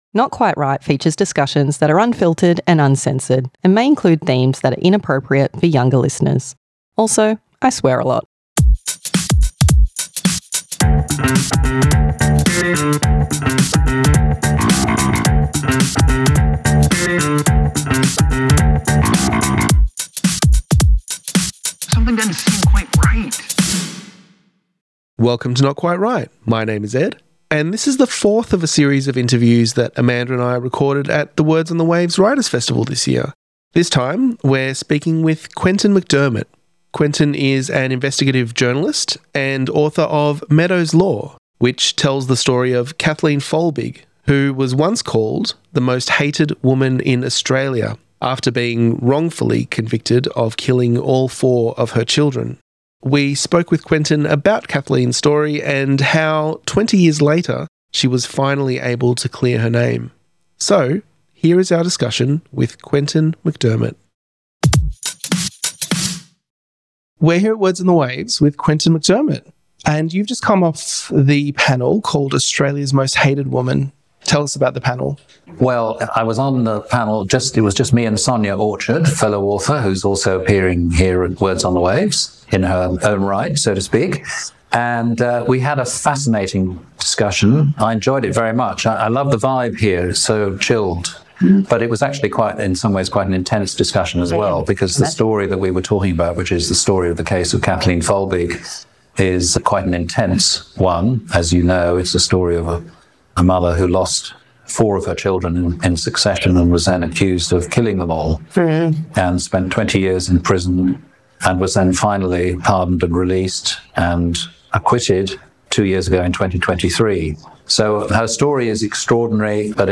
Not Quite Write Interviews